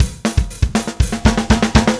Power Pop Punk Drums 01 Fill B.wav